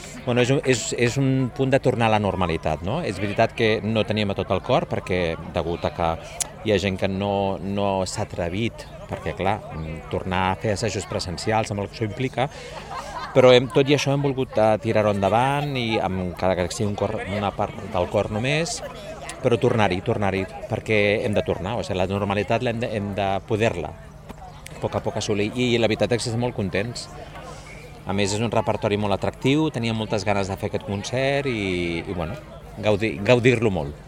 Declaracions: